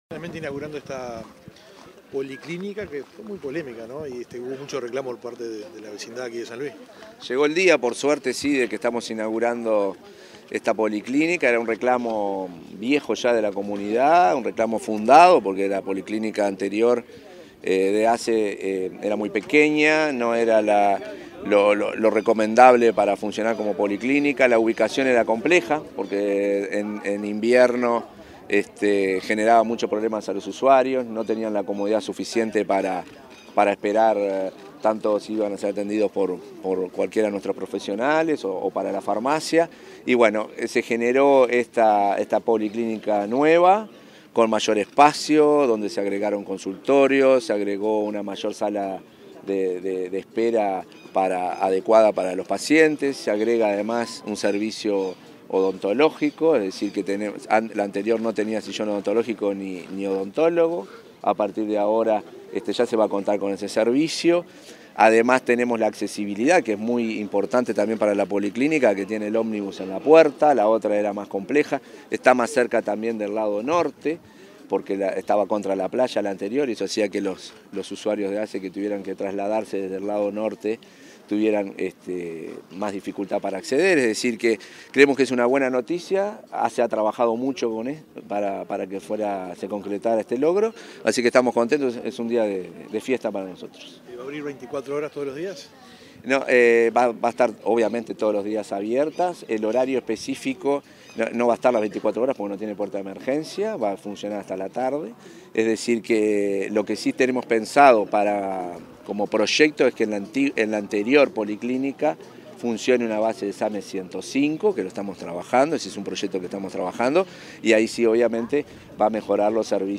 Declaraciones del presidente de ASSE, Marcelo Sosa 11/06/2024 Compartir Facebook X Copiar enlace WhatsApp LinkedIn Tras la inauguración de la policlínica de San Luis, que depende de la Red de Atención Primaria de Canelones, el presidente de la Administración de los Servicios de Salud del Estado (ASSE), Marcelo Sosa, realizó declaraciones a la prensa.